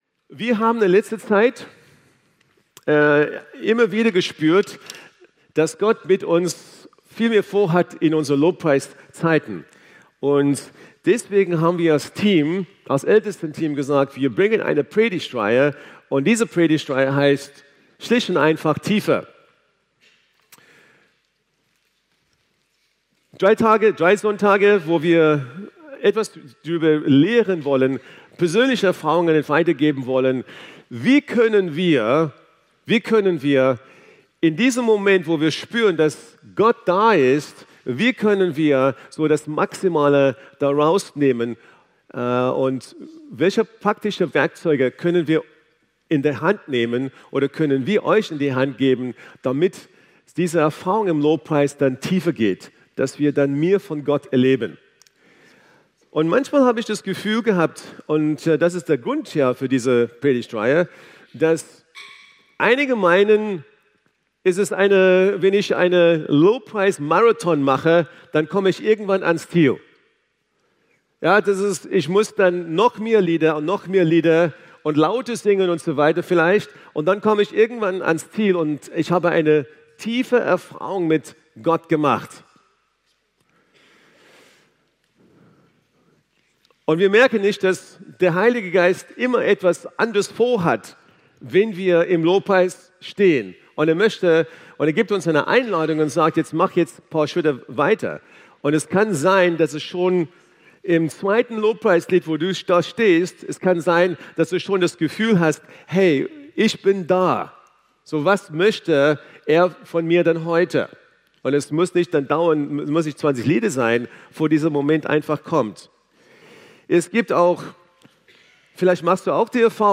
Eine predigt aus der serie "Tiefer."